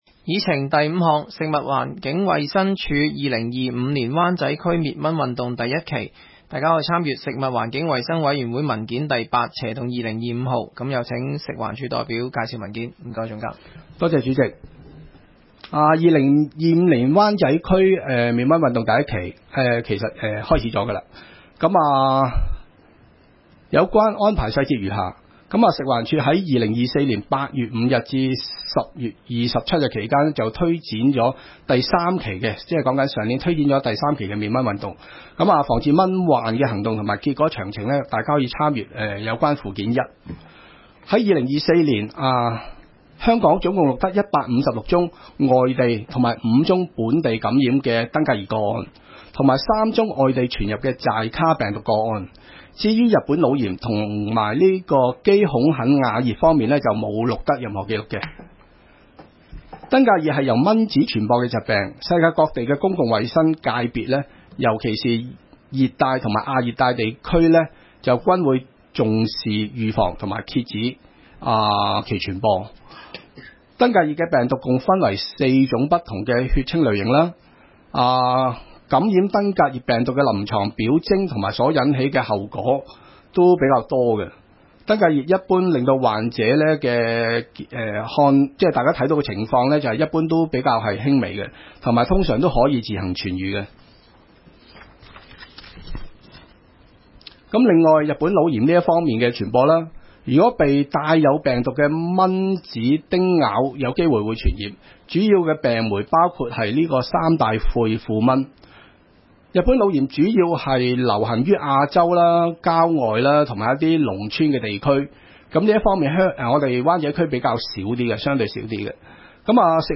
湾仔区议会 - 委员会会议的录音记录
工作小组会议的录音记录